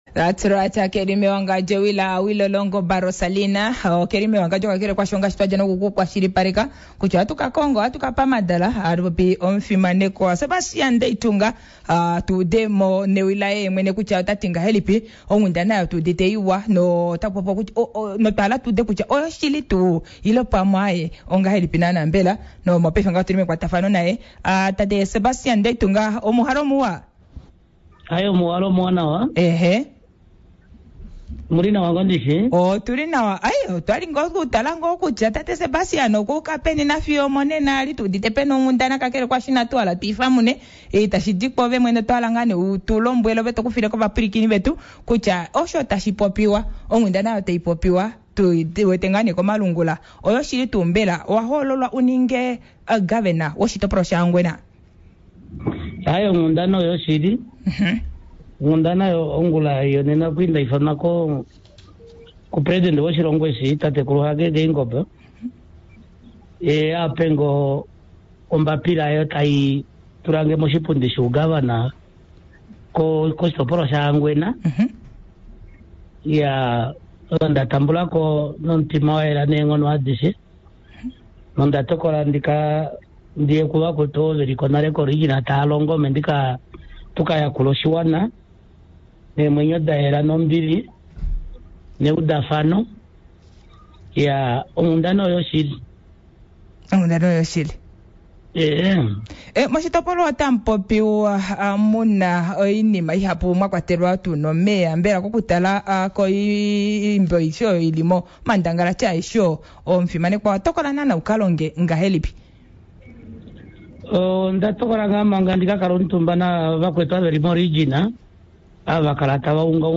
In the interview with former Inspector General of the Namibian Police Force Sebastian Ndeitunga talking about his new position as new Governor of Ohangwena, were he said he will work together with the former governor like Uusuko Ngaamwa, Billy Mwaningange and Ndevashiya.